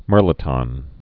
(mîrlĭ-tŏn, mîr-lē-tôɴ)